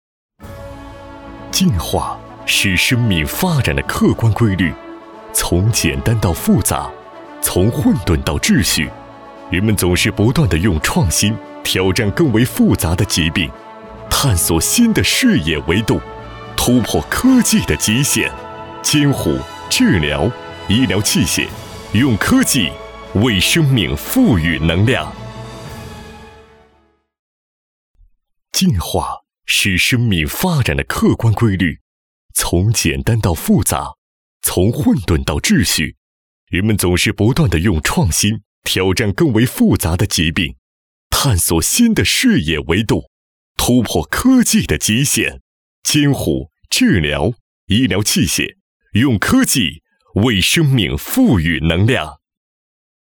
162男-大气恢宏
特点：大气浑厚 稳重磁性 激情力度 成熟厚重
风格:浑厚配音